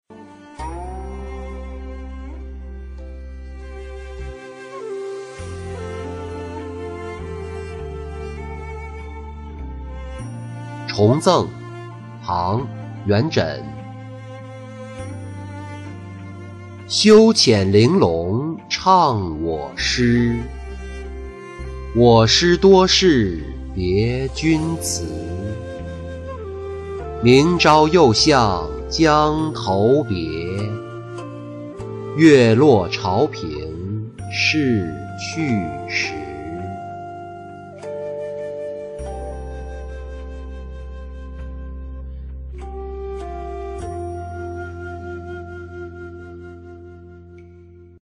重赠-音频朗读